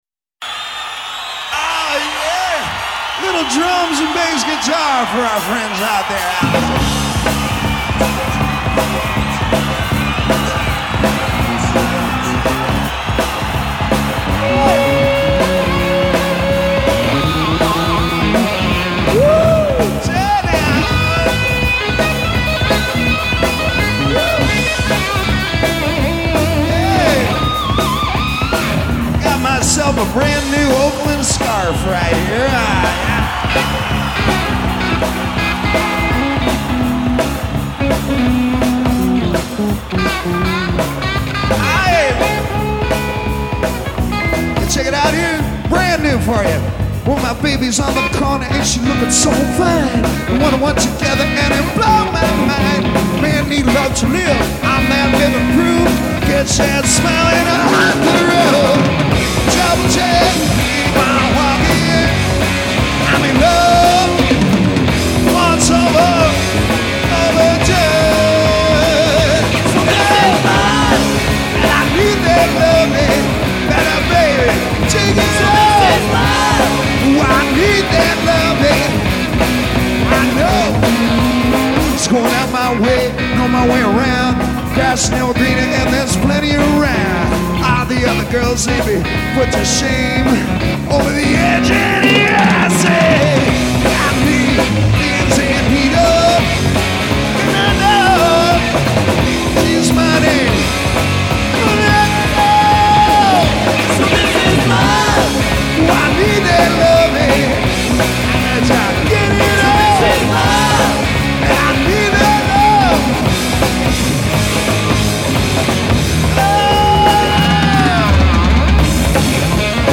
while playing Oakland in June 1981